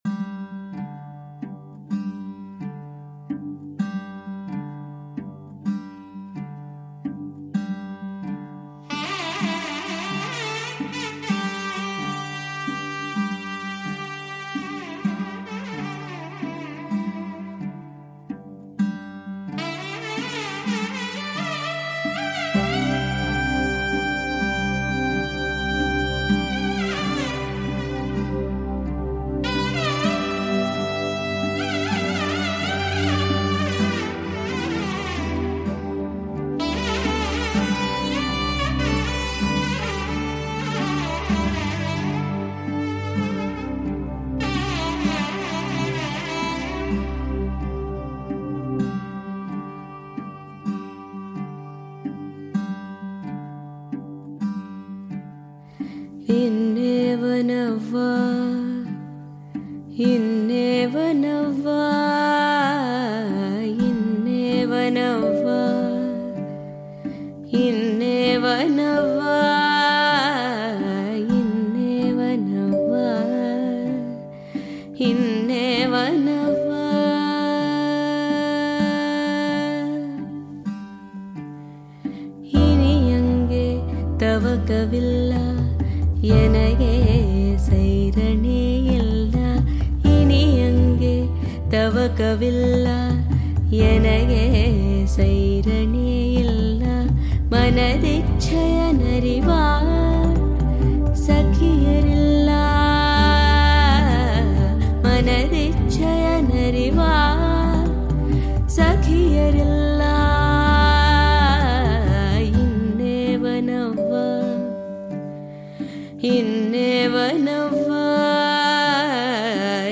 Музыка для Инстаграм красивая индийская мелодия